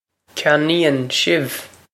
Pronunciation for how to say
kyan-een shiv
This is an approximate phonetic pronunciation of the phrase.